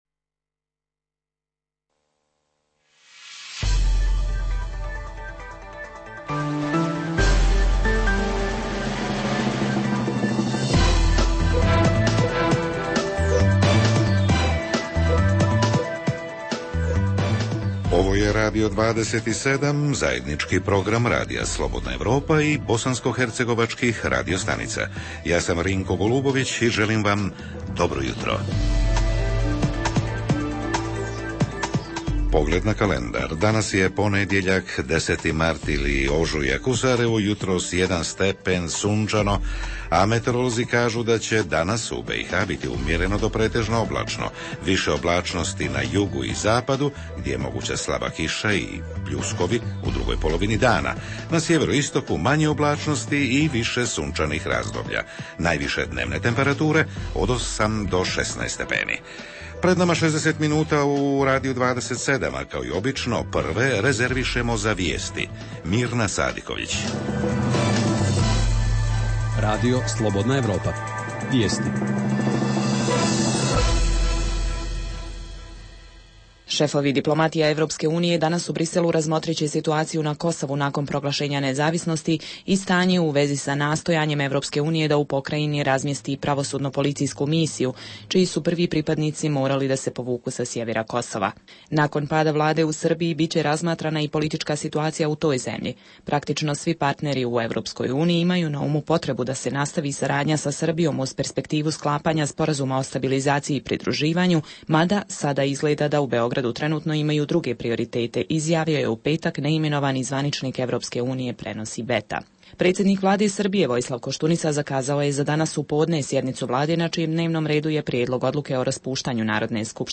Jutarnji program za BiH koji se emituje uživo. Ponedeljkom sadrži informacije, teme i analize o dešavanjima u BiH i regionu, a reporteri iz cijele BiH javljaju o najaktuelnijim događajima proteklog vikenda.
Redovni sadržaji jutarnjeg programa za BiH su i vijesti i muzika.